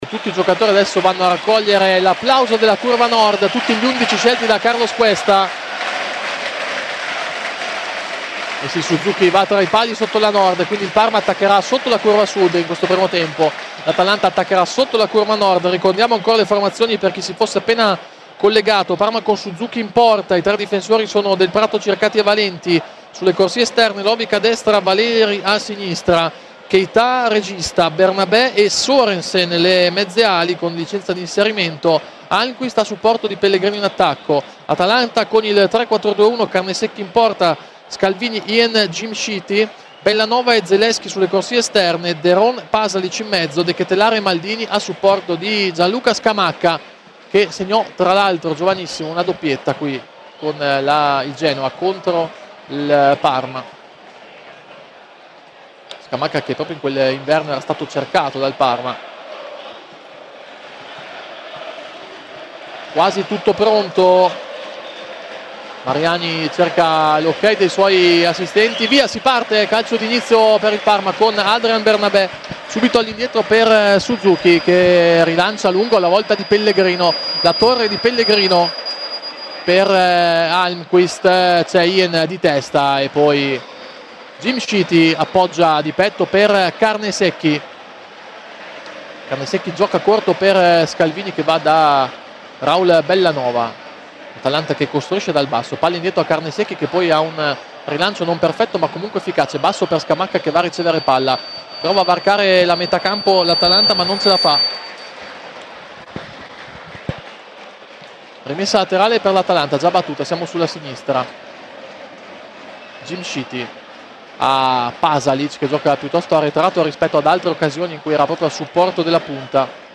Parma-Atalanta, le formazioni: Sorensen unica novità nel Parma con i “nuovi” tutti in panchina. Radiocronaca